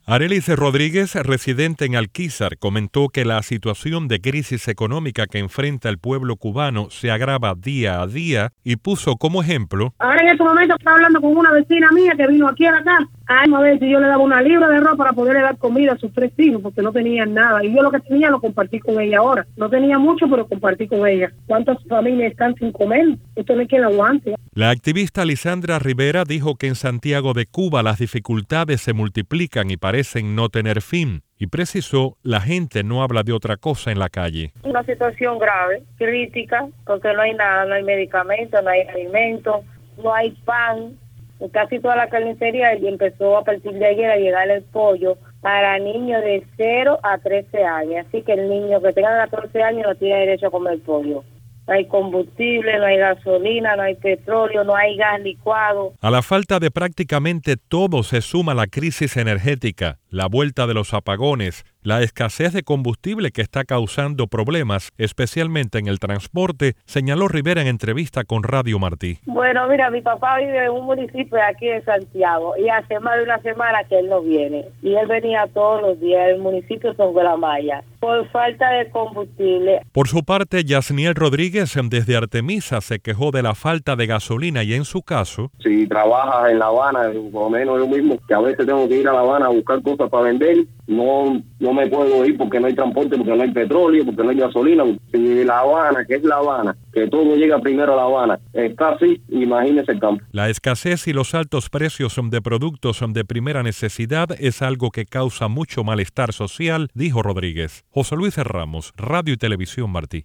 Cubanos en la isla se quejan de la situación económica y social en Cuba